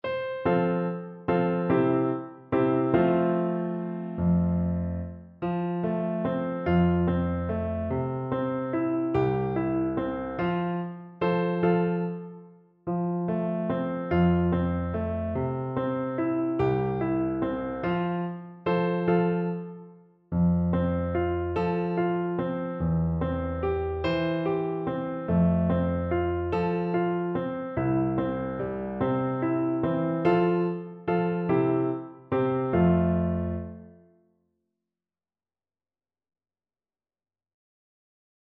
Traditional Music of unknown author.
3/4 (View more 3/4 Music)
One in a bar =c.145
F major (Sounding Pitch) C major (French Horn in F) (View more F major Music for French Horn )